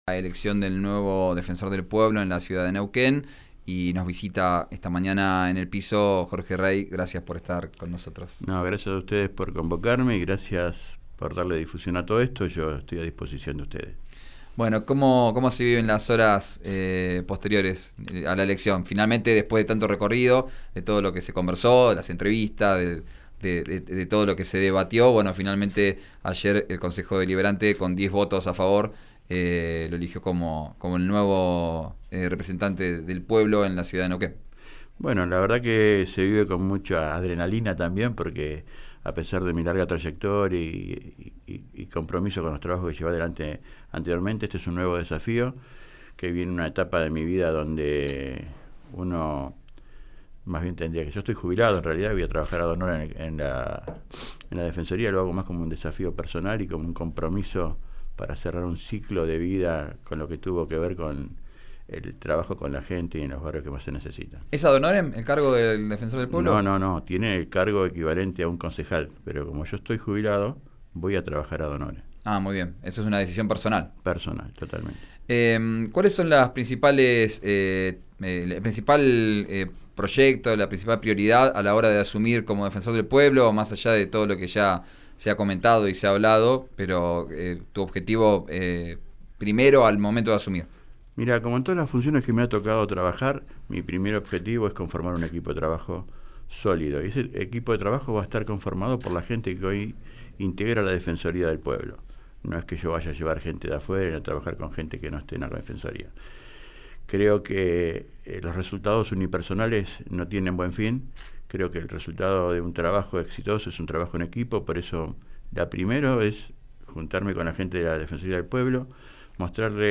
El exconcejal del bloque del partido provincial visitó el estudio de RÍO NEGRO RADIO.